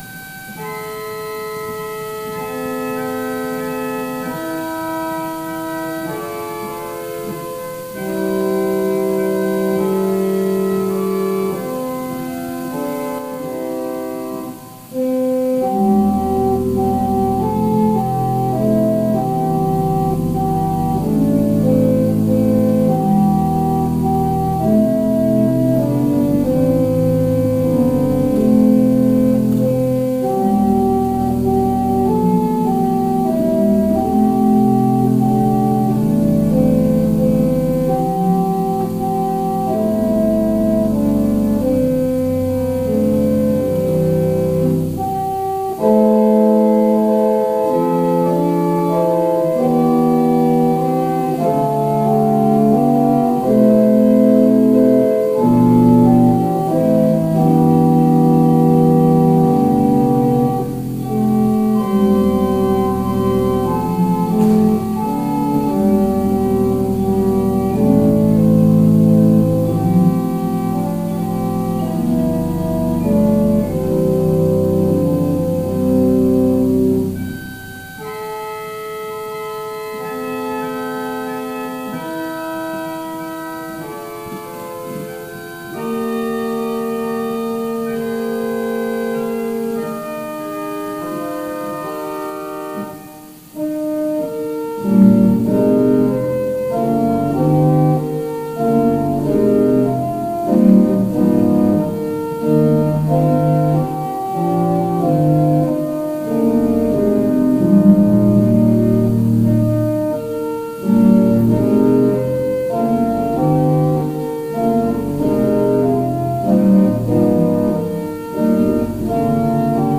Prelude: “I Would See Jesus” by Edward Broughton, based on a “Sacred Harp” tune by L. P. Breedlove
Call to Worship / Invocation / Lord’s Prayer